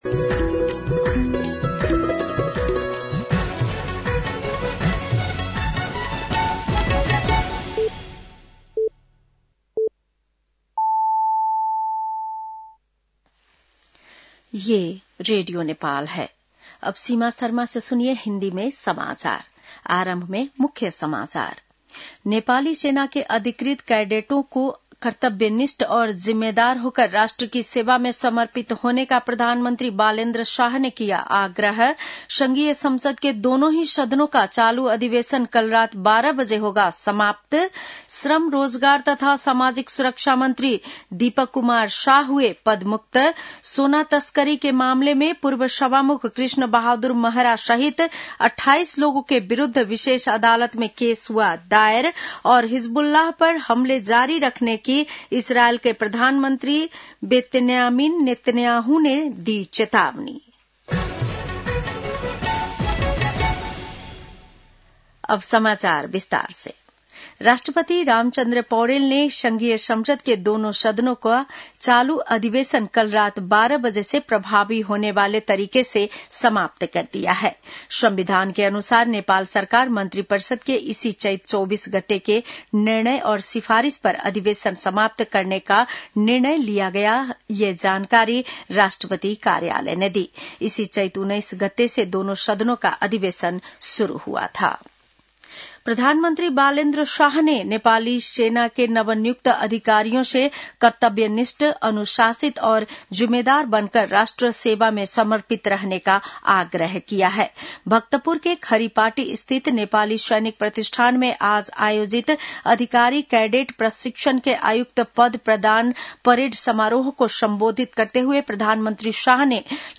An online outlet of Nepal's national radio broadcaster
बेलुकी १० बजेको हिन्दी समाचार : २६ चैत , २०८२